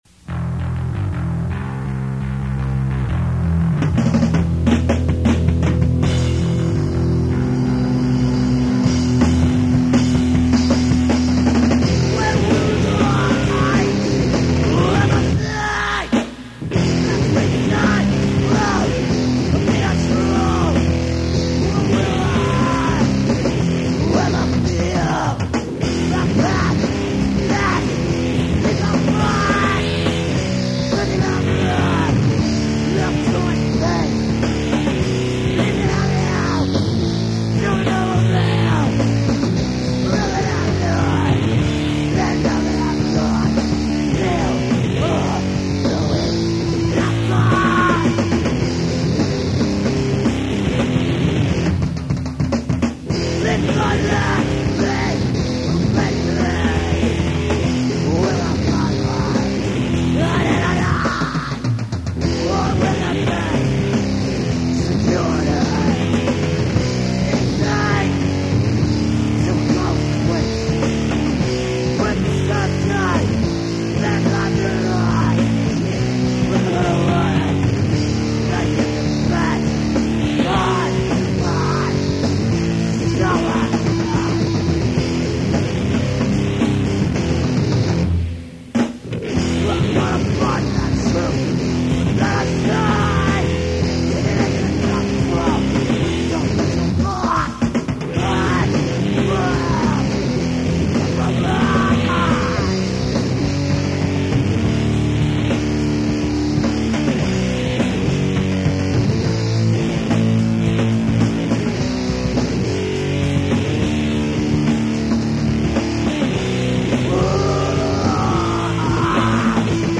with different lyrics and played a little slower